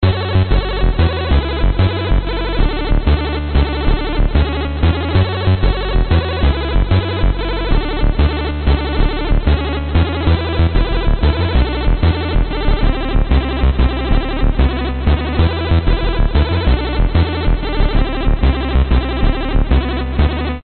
汽车引擎记录
描述：汽车引擎记录。这是一台带有增压器的测功机上的GT86的现场录音。车辆通过设备，然后从第四档以3000RPM转到7200转。
标签： 实地 Vortech 增压器 DYNO 幼芽 丰田 引擎 斯巴鲁 记录 汽车
声道立体声